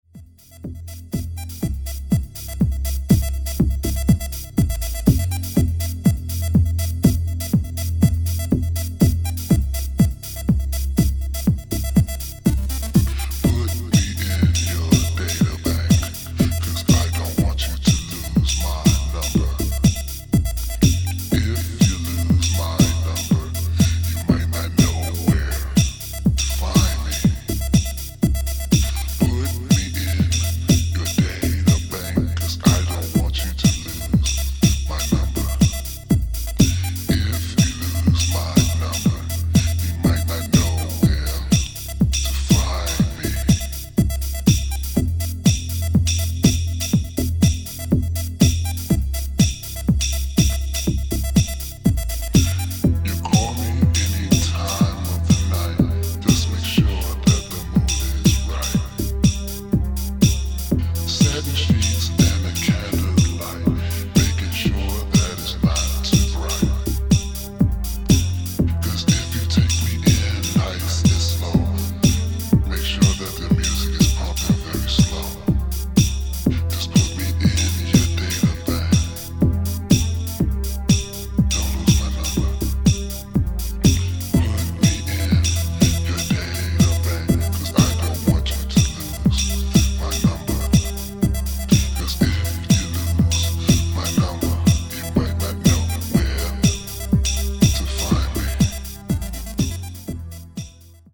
ムーディーでアンダーグランドな傑作ディープ・ハウスを計4トラック収録！
[90SHOUSE]